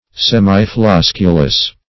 Search Result for " semiflosculous" : The Collaborative International Dictionary of English v.0.48: Semiflosculous \Sem`i*flos"cu*lous\, a. (Bot.)
semiflosculous.mp3